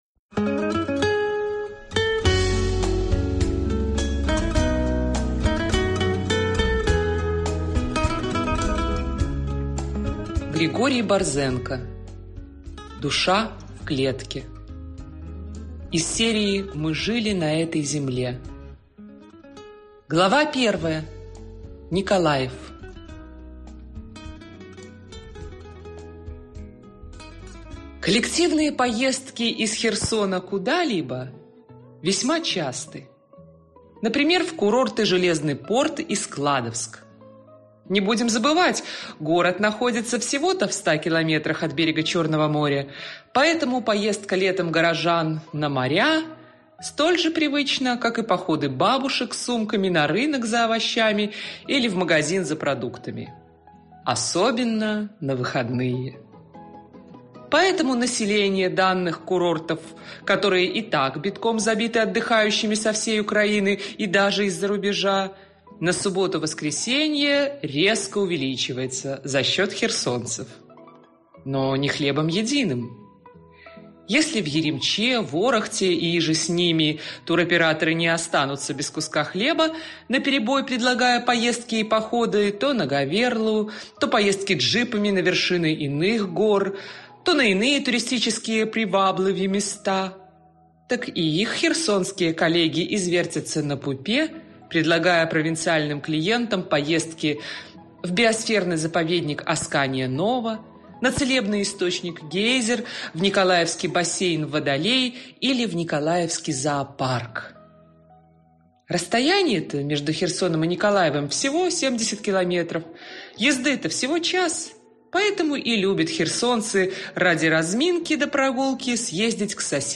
Аудиокнига Душа в клетке | Библиотека аудиокниг